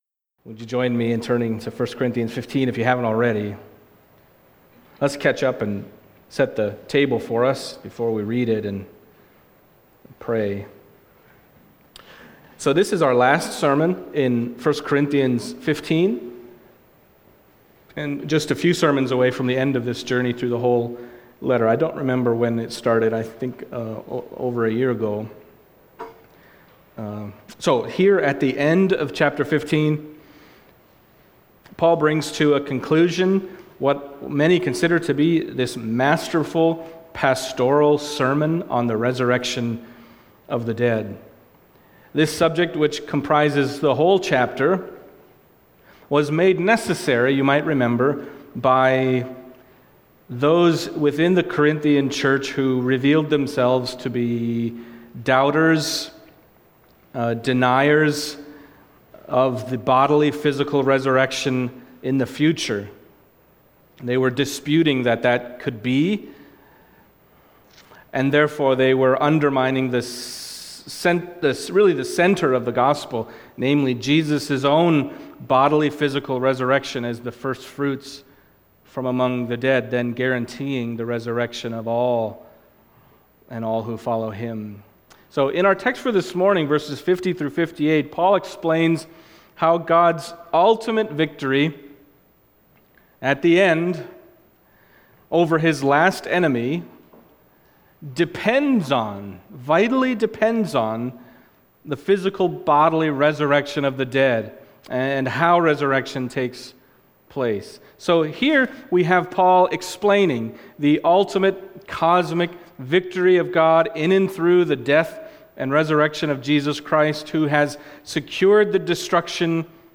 1 Corinthians Passage: 1 Corinthians 15:50-58 Service Type: Sunday Morning